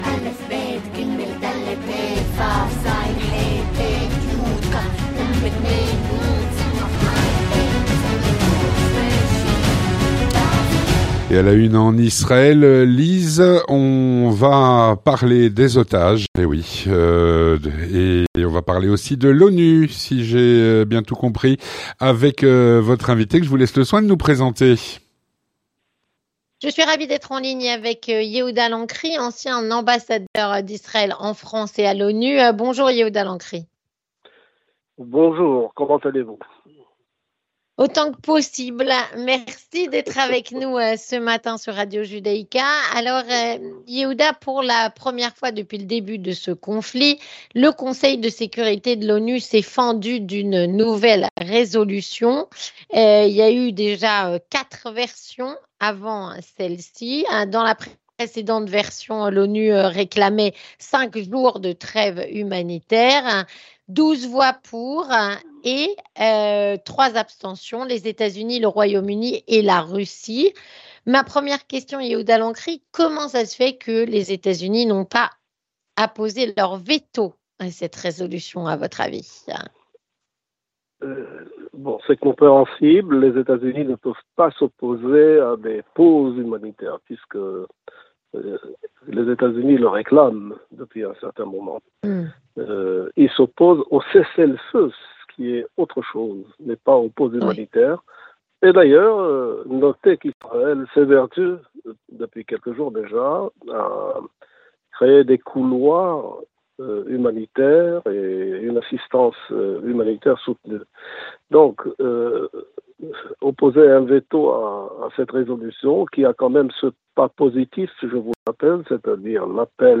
Avec Yehuda Lancry, ancien ambassadeur d'Israël à l'ONU et en France